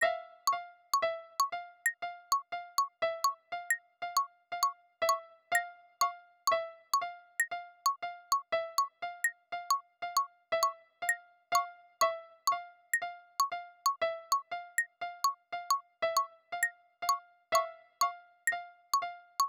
The standard Qtractor metronome sound, logically corresponds to the audio metronome. I have assigned a piano sound to the midi metronome to better differentiate it.
metro-audio+midi.flac